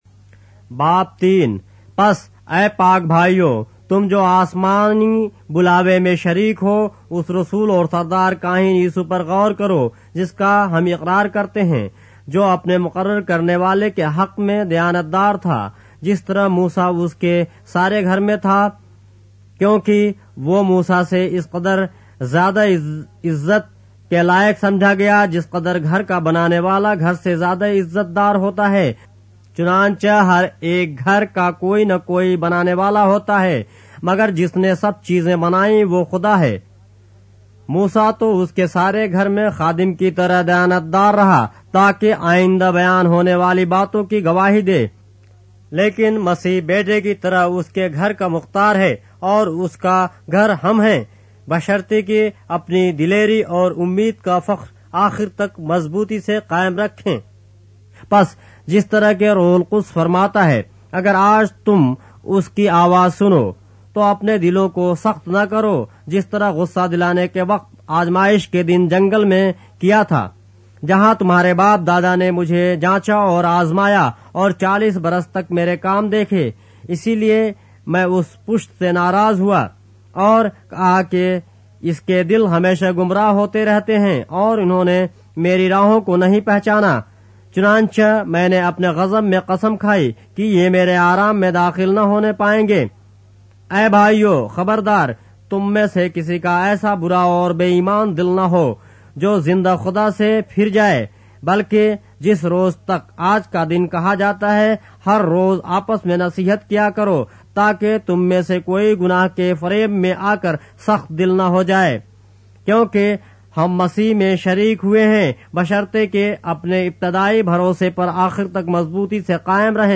اردو بائبل کے باب - آڈیو روایت کے ساتھ - Hebrews, chapter 3 of the Holy Bible in Urdu